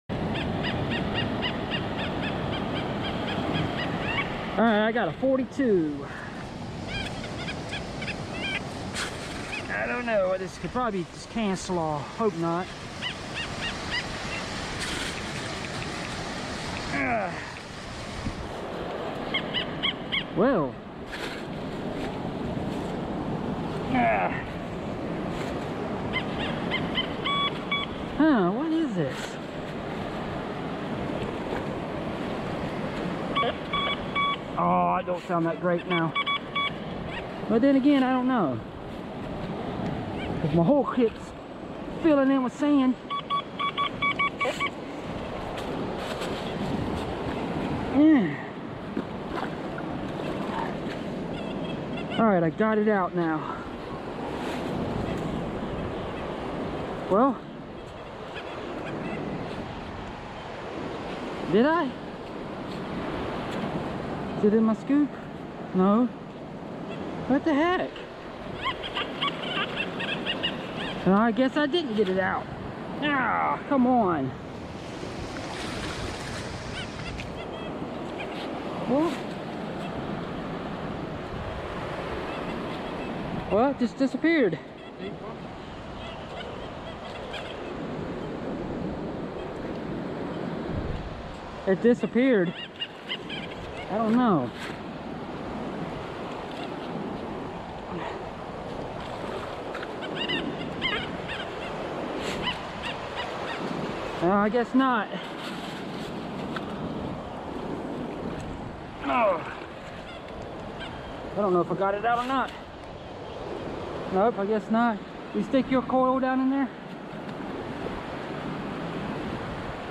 Been out on the beach all day and then my metal detector started going off with a sound and dug deep to see what treasure